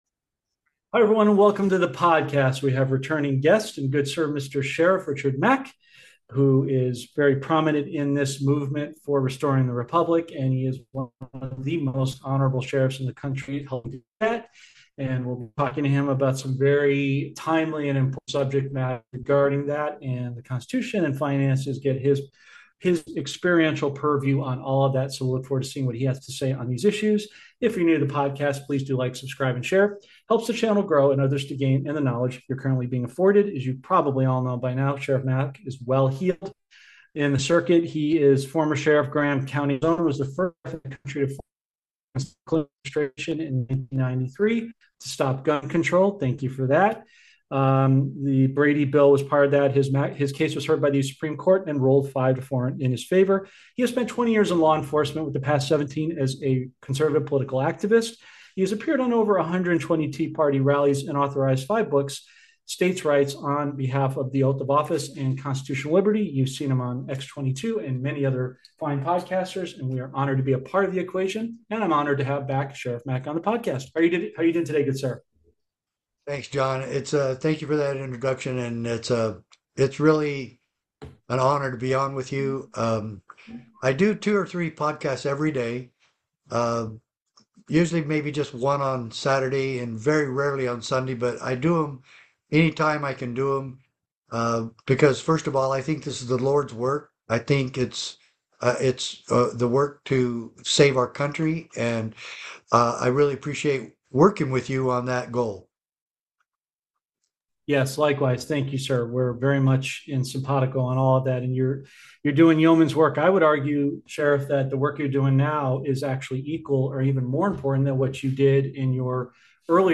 In this podcast, the host interviews Sheriff Richard Mack, a prominent figure in the movement for restoring the Republic.